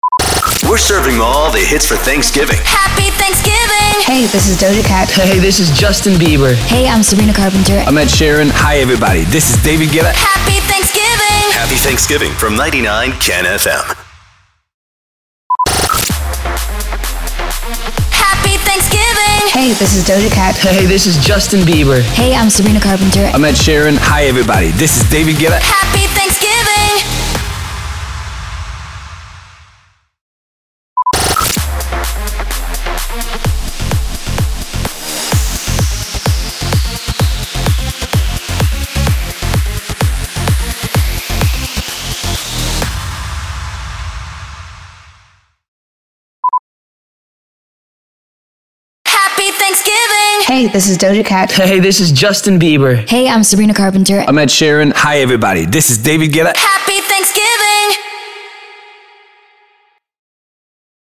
754 – SWEEPER – THANKSGIVING – ARTIST MONTAGE
754-SWEEPER-THANKSGIVING-ARTIST-MONTAGE.mp3